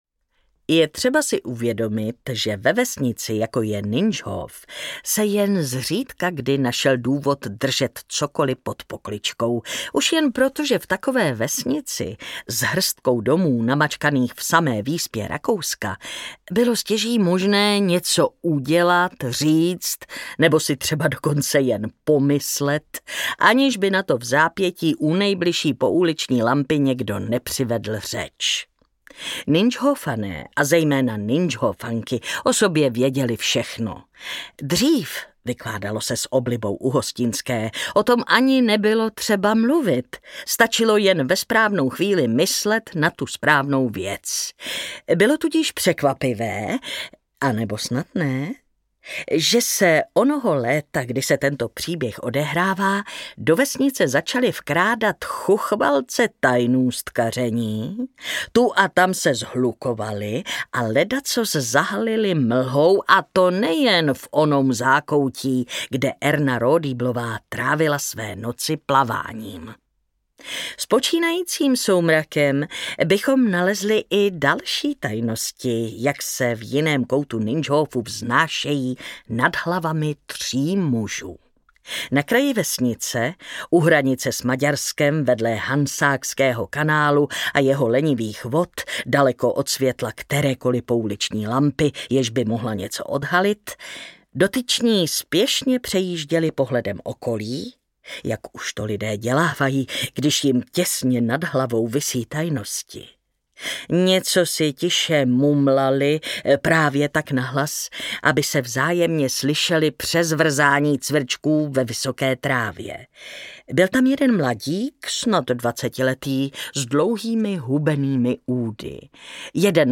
Ukázka z knihy
Čte Martina Hudečková.
Vyrobilo studio Soundguru.
• InterpretMartina Hudečková